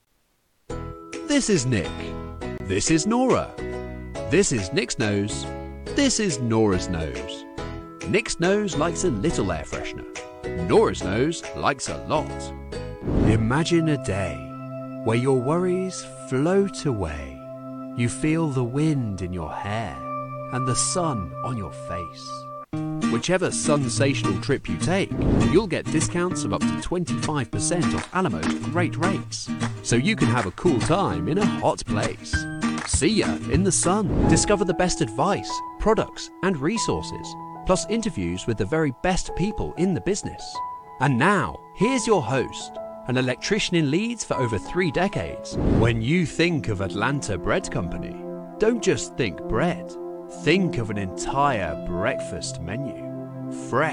外籍英式英语